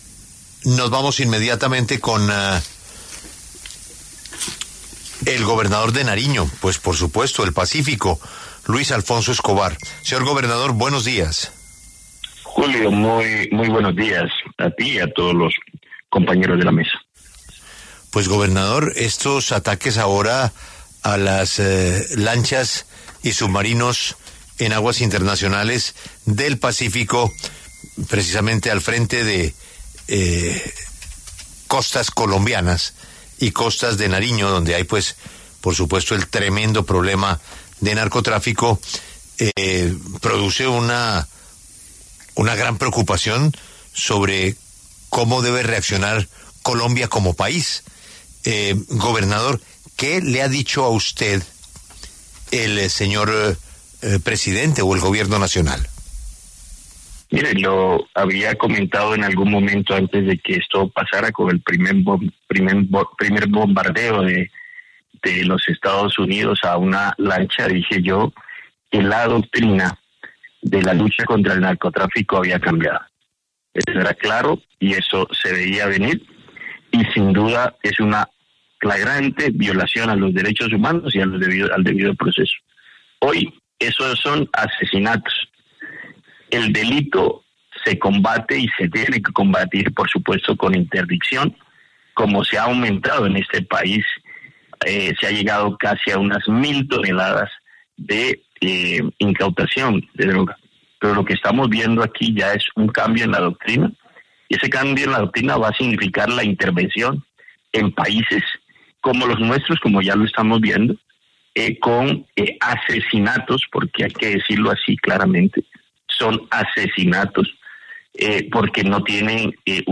En diálogo con La W, el gobernador de Nariño, Luis Alfonso Escobar, manifestó su preocupación por los bombardeos en aguas del pacífico por parte de los Estados Unidos.